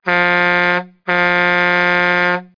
trkhrn04.mp3